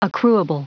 Prononciation du mot accruable en anglais (fichier audio)
Prononciation du mot : accruable